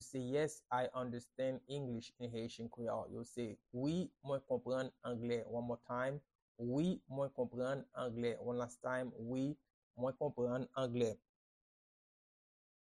Pronunciation and Transcript:
Yes-I-understand-English-in-Haitian-Creole-Wi-mwen-konprann-angle-pronunciation-by-a-Haitian-teacher.mp3